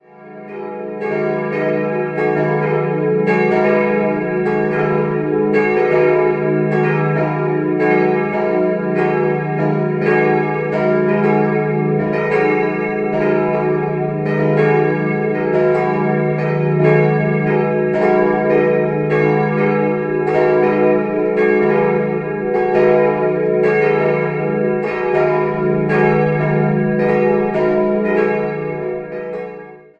Die Glocken der Pfarrkirche Mariä Himmelfahrt in Eutenhofen
Im geräumigen Chorturm der Eutenhofener Kirche hängen drei Gussstahlglocken des Bochumer Vereins aus dem Jahr 1948, seit dem Jahr 2016 in einem neuen Holzglockenstuhl.
Ein für die Sekundschlagrippe klanglich durchaus ansprechendes Geläut kann somit auch noch zukünftig seinen Dienst verrichten.
Schlagton: gis¹-1
Schlagton: fis¹+2
Schlagton: dis¹+2